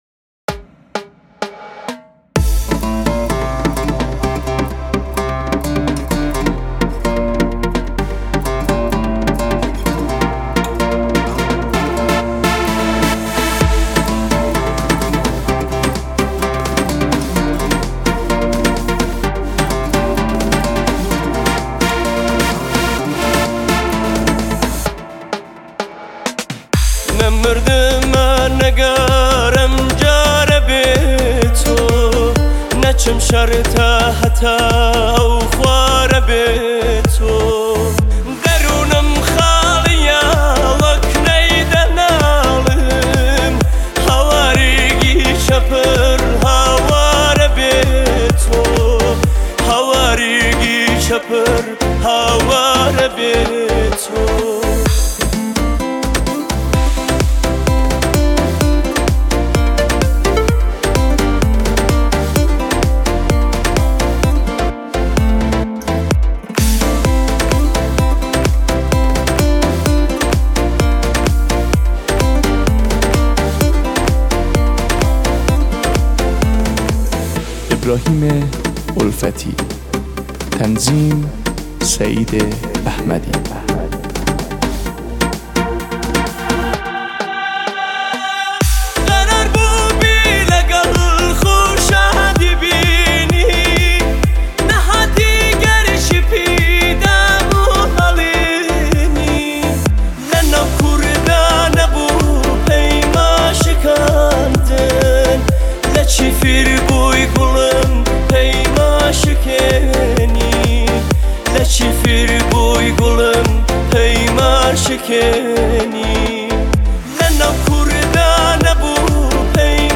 آهنگ شاد کردی